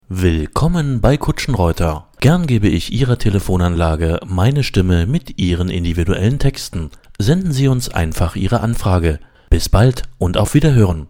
Im Studio werden Ihre Ansagen individuell für Sie produziert – KI-generierte Ansagen oder Texte ‚von der Stange‘ gibt’s bei uns nicht.